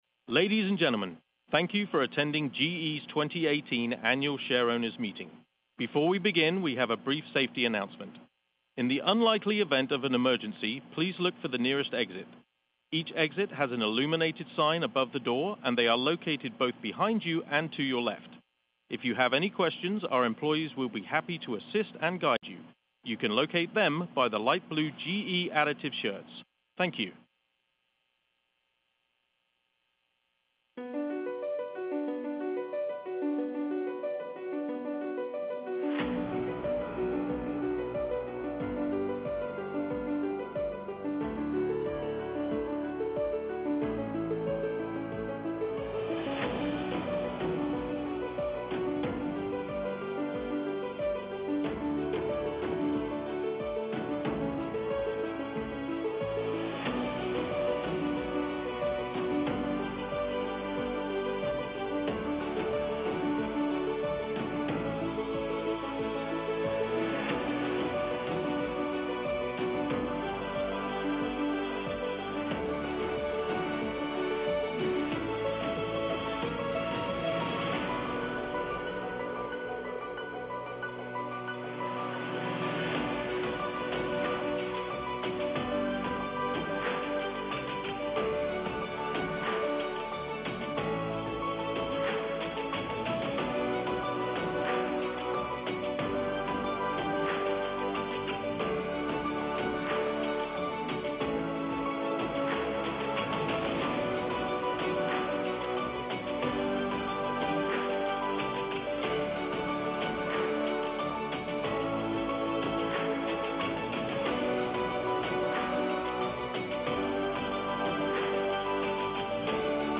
GE Chairman and CEO John Flannery presented.
2018 Annual Meeting of Shareowners_0.mp3